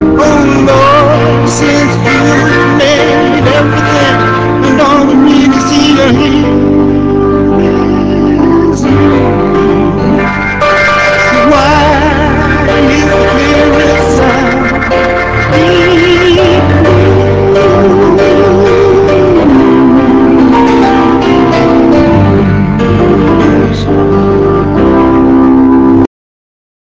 Voice and Piano
Hammond B3 Organ